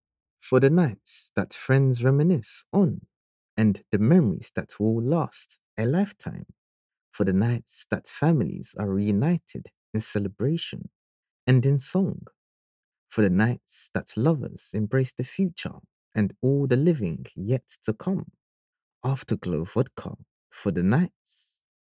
English (Caribbean)
Adult (30-50) | Yng Adult (18-29)